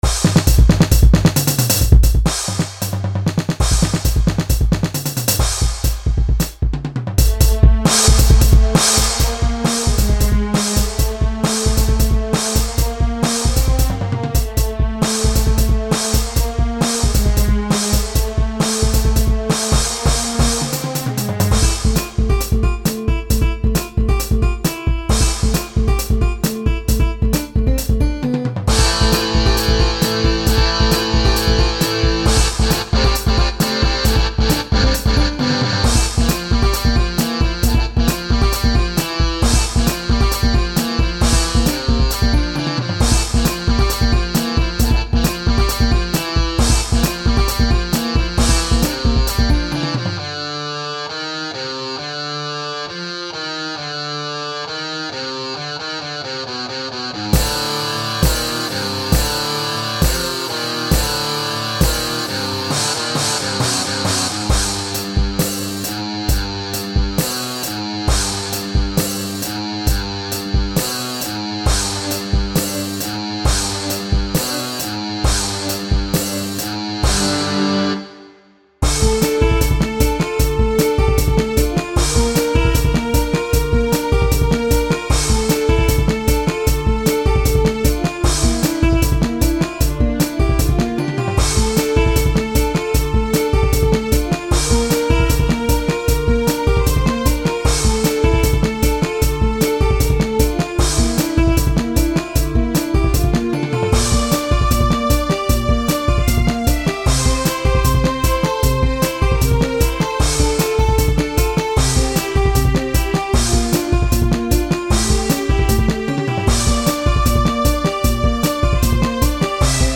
Ударные там вообще не к месту, натыканы как попало.
fight-themeall.mp3